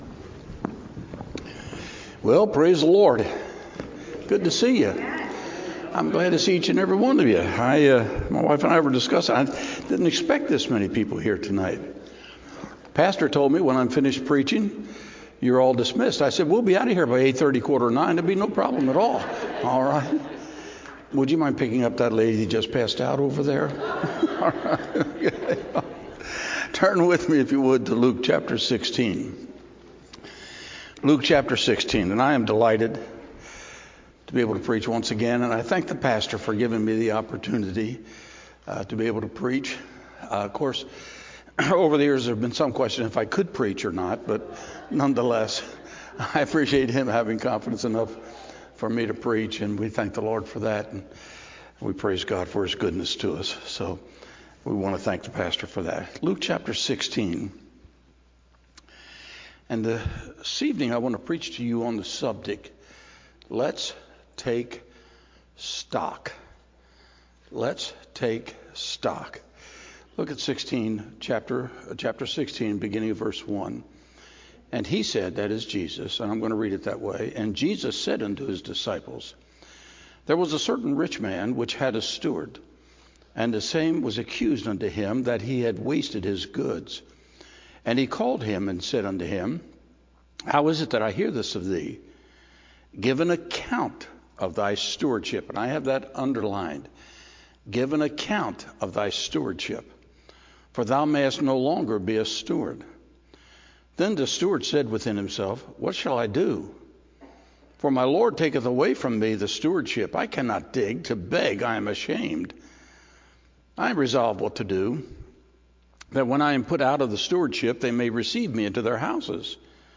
Sunday PM Service